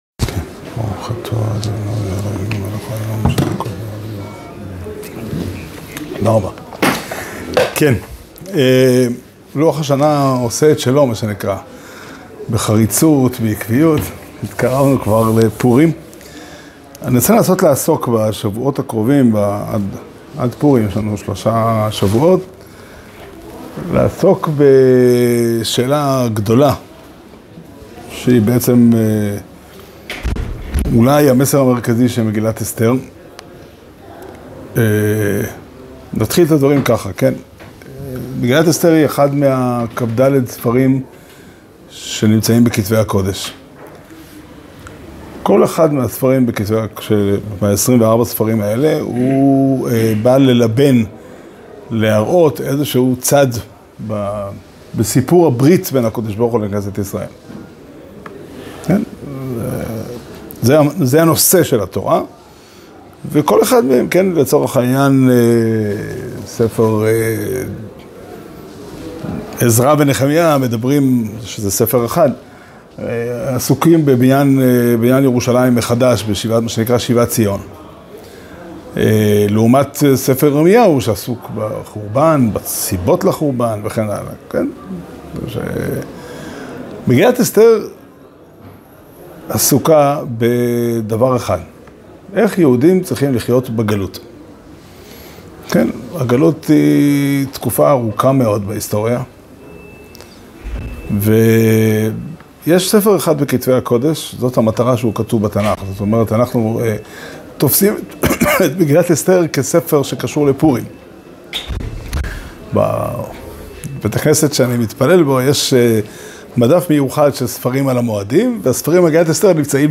שיעור שנמסר בבית המדרש פתחי עולם בתאריך כ"ה אדר א' תשפ"ד